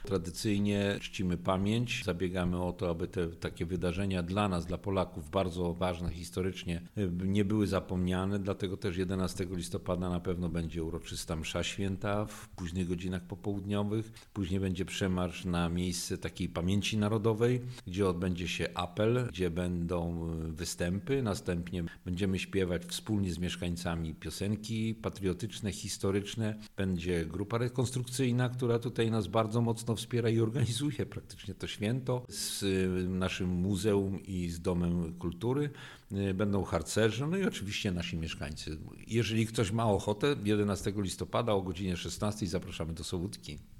– We wtorek, 11 listopada, będzie się u nas wiele działo – zaznacza Mirosław Jarosz, burmistrz Miasta i Gminy Sobótka.